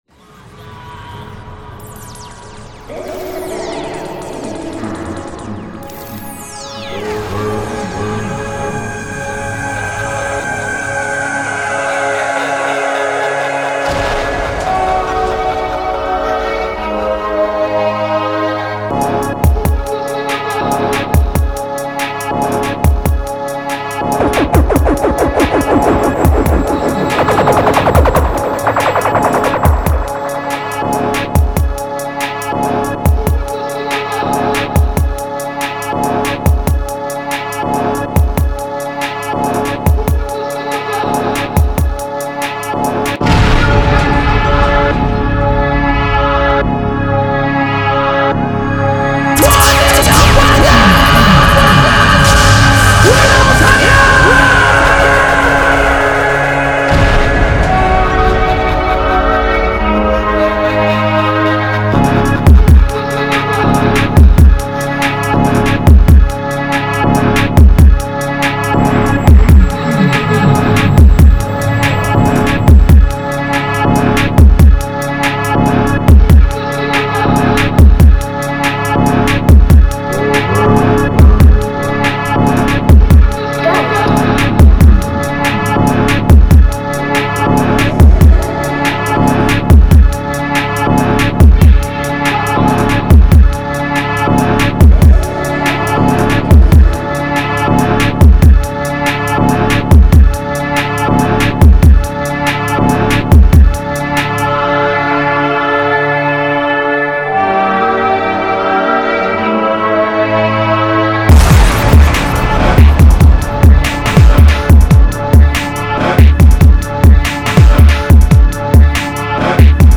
PETITE MIXTAPE ET APERÇU DE MES PRODUCTIONS.